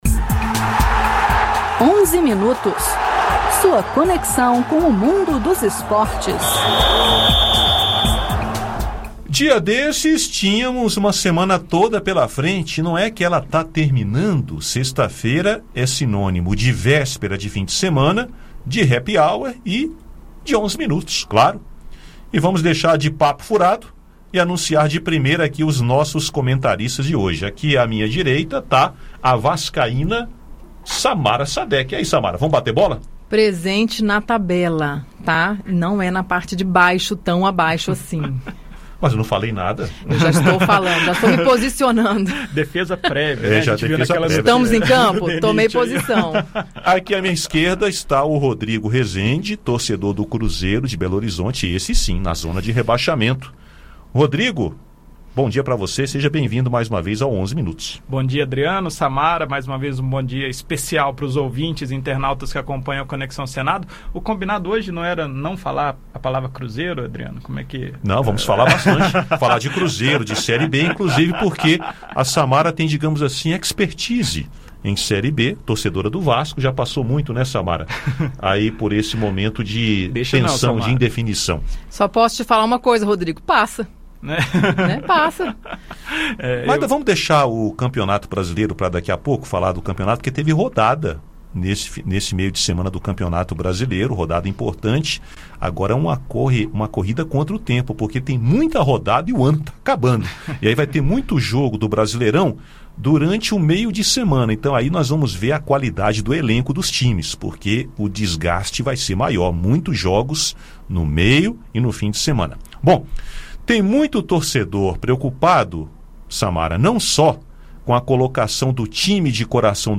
Além dos comentários sobre os campeonatos. Ouça o áudio com o bate-papo.